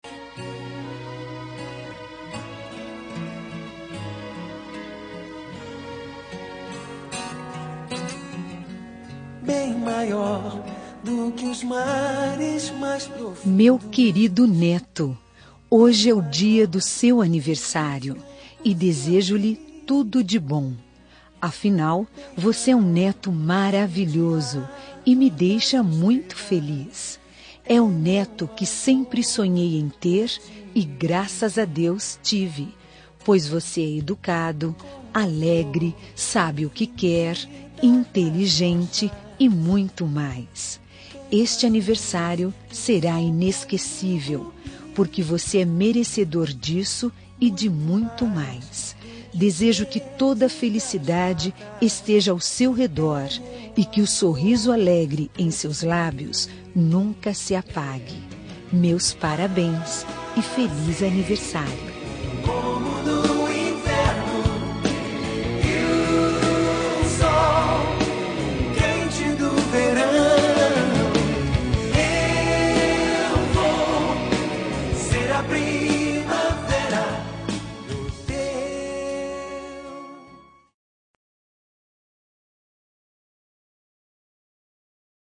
Aniversário de Neto – Voz Feminina – Cód: 131040